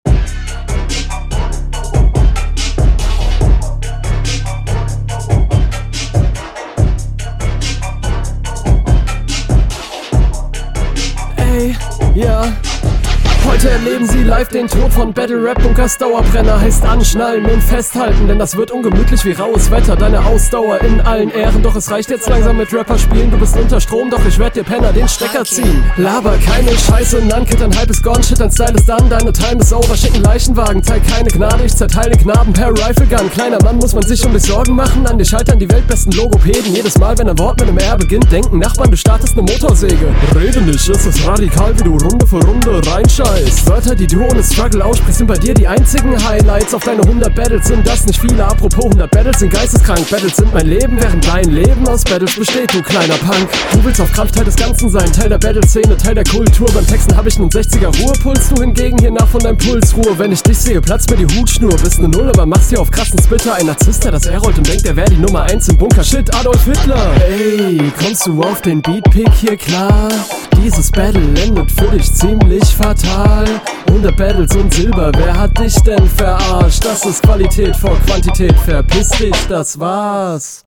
Der Beat ist auch cool und steht dir viel besser.